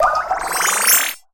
water_bubble_spell_heal_04.wav